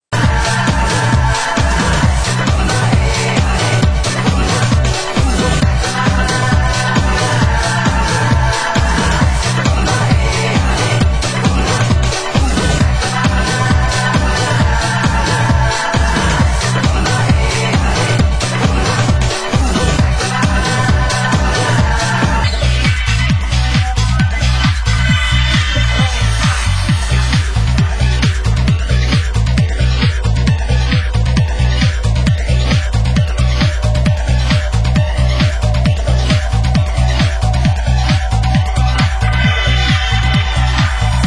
HomeElectro / New grooves  >  Deep House